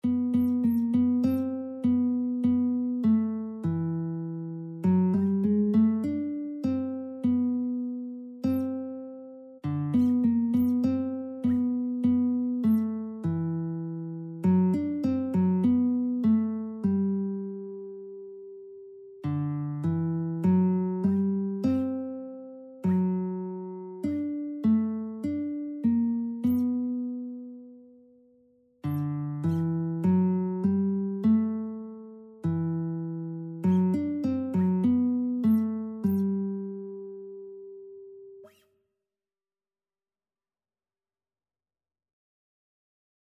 Christian Christian Lead Sheets Sheet Music He Keeps Me Singing
4/4 (View more 4/4 Music)
G major (Sounding Pitch) (View more G major Music for Lead Sheets )